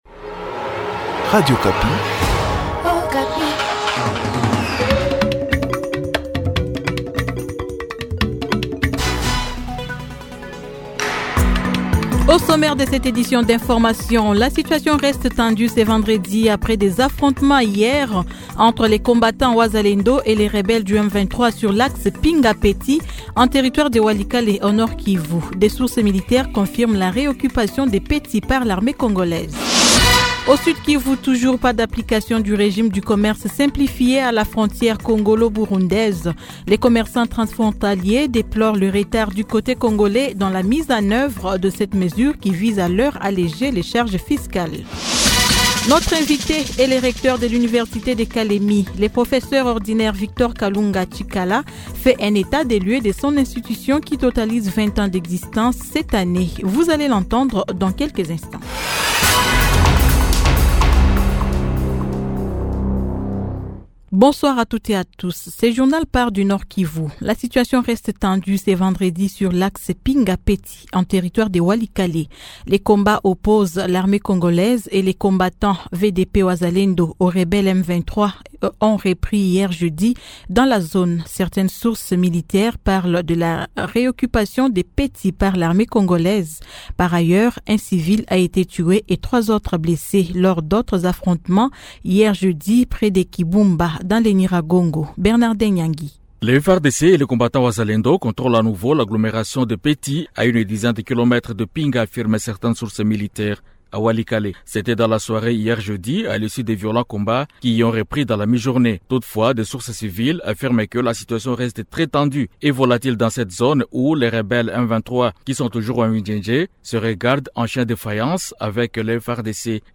Journal Soir 18H00